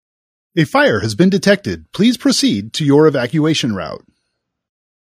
Fire
fire.mp3